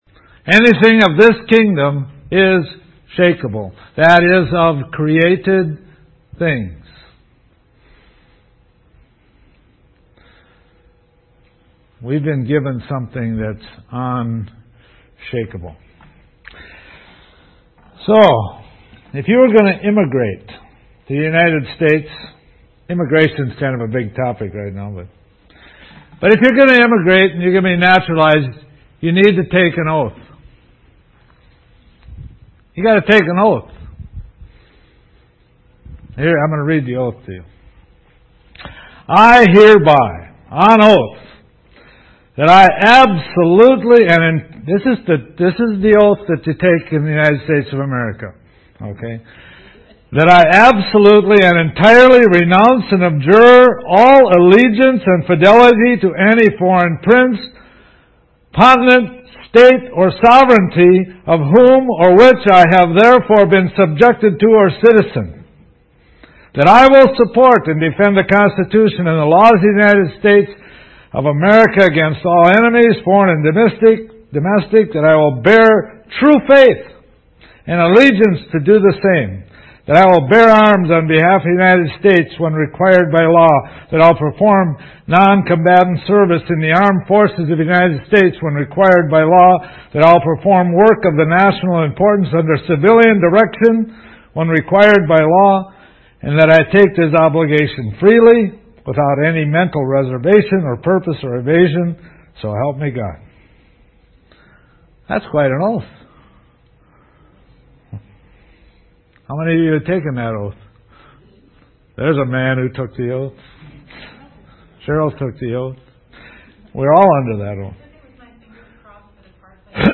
I Have an Attitude This entry was posted in sermons .